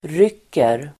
Uttal: [r'yk:er]